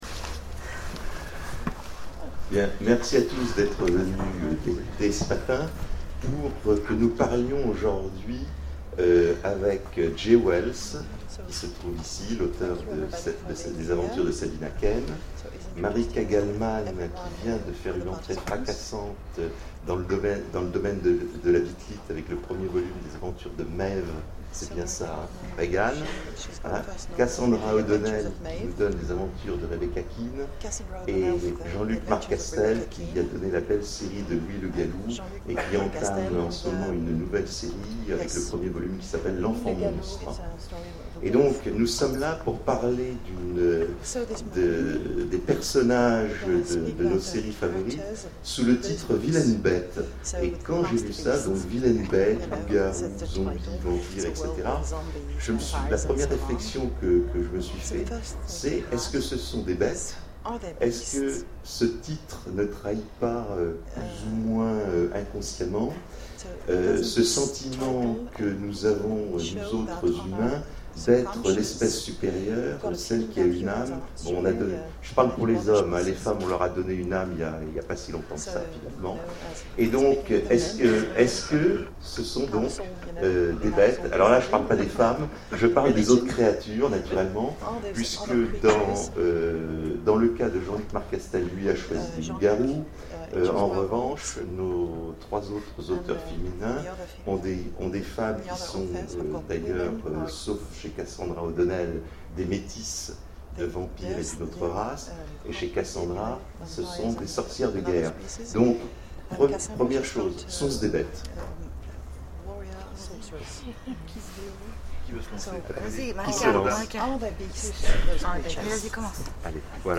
Mots-clés Loup-garou Vampire Zombies Conférence Partager cet article